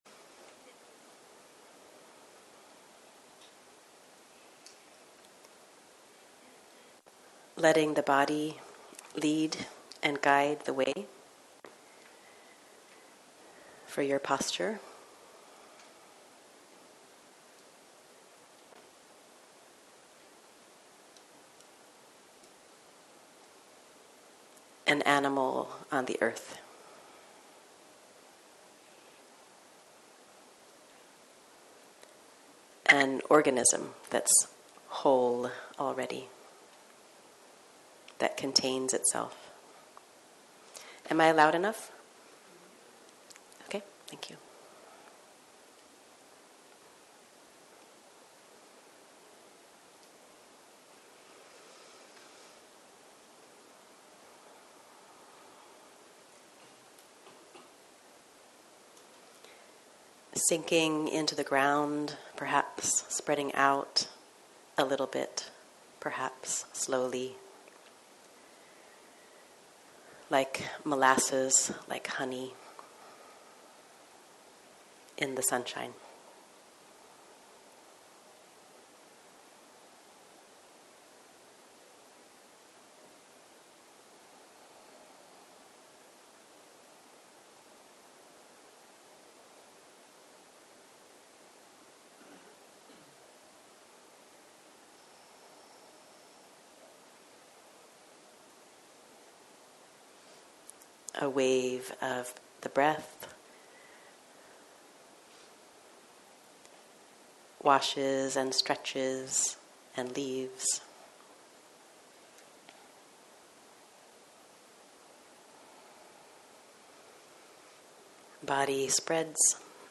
בוקר - מדיטציה מונחית.